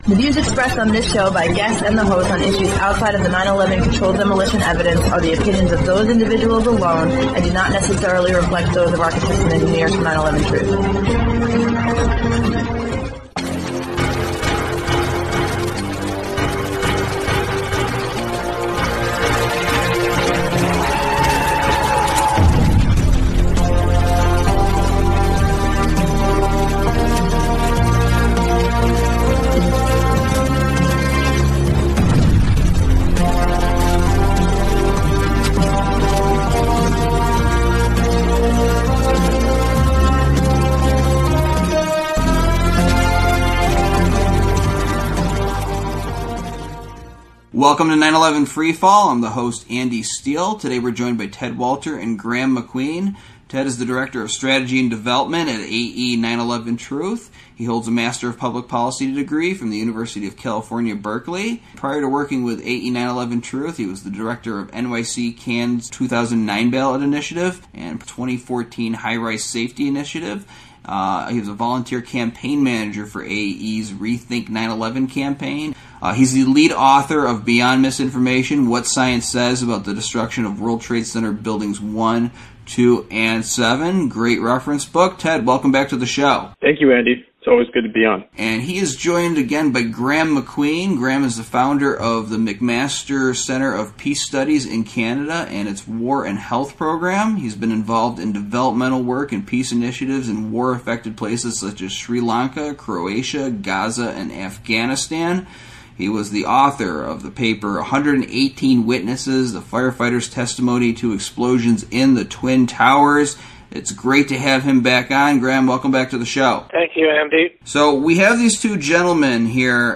911 Free Fall Talk Show